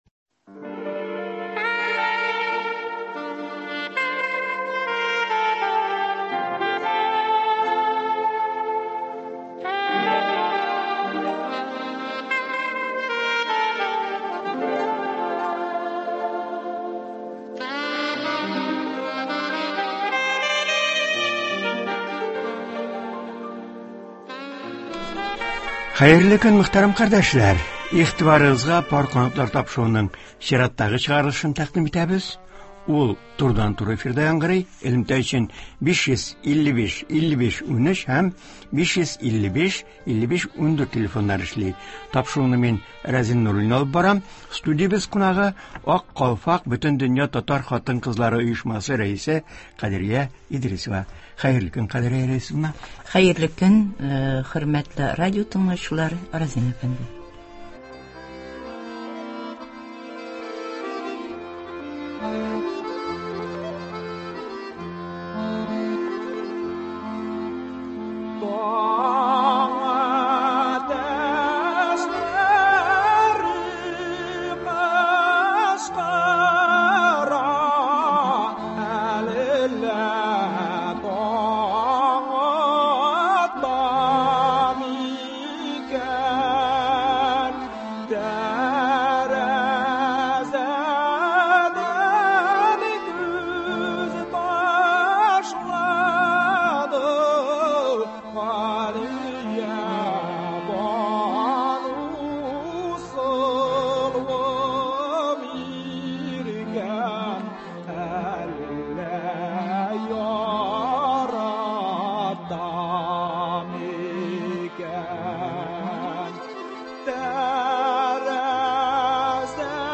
тыңлаучылар сорауларына җавап бирәчәк.